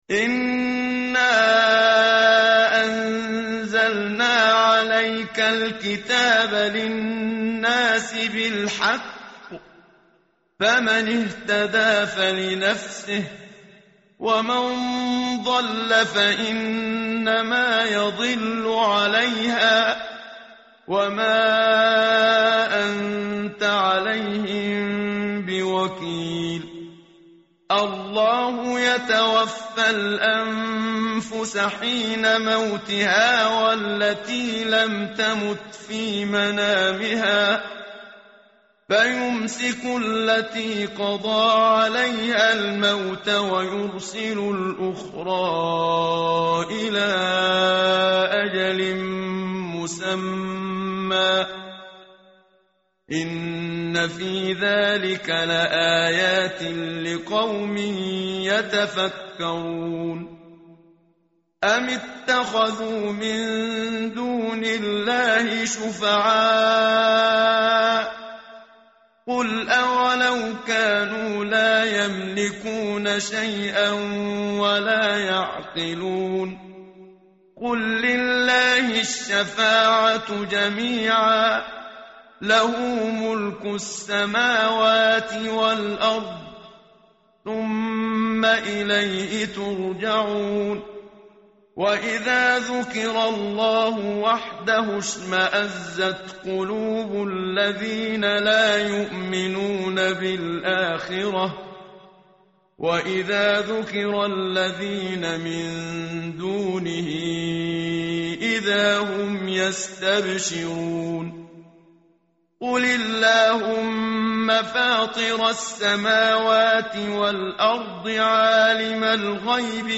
tartil_menshavi_page_463.mp3